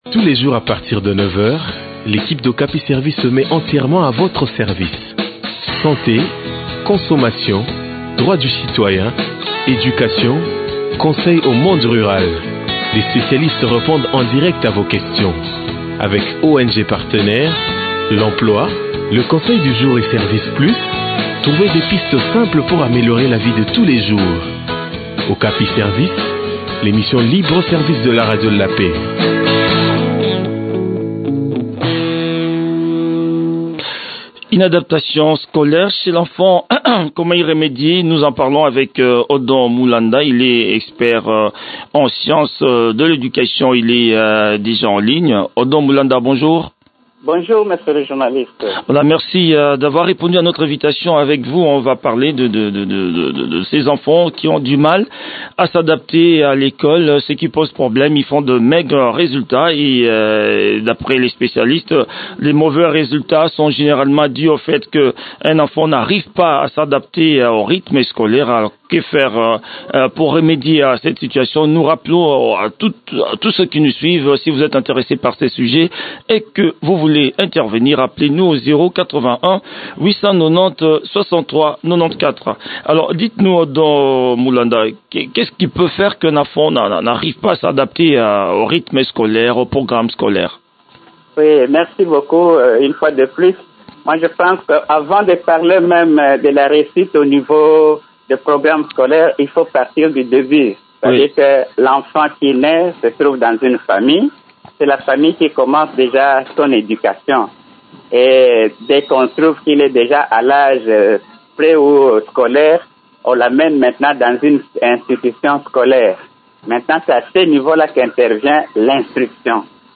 expert en sciences de l’éducation.